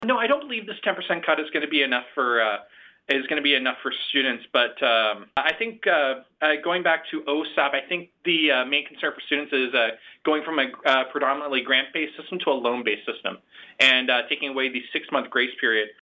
In an interview Tuesday